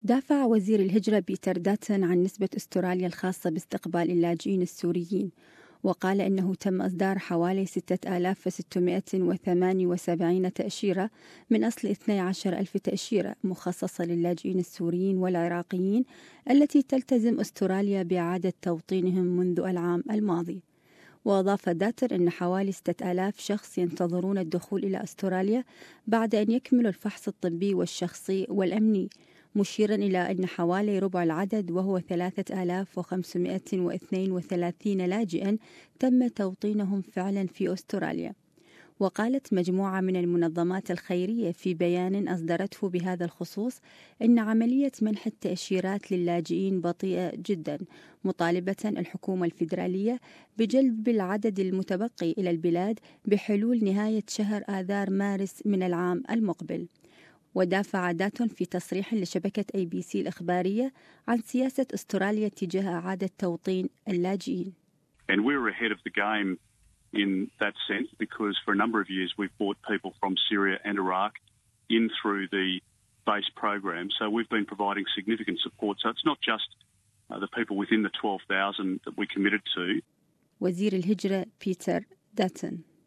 But speaking to the A-B-C, Mr Dutton defended Australia's refugee resettlement rate.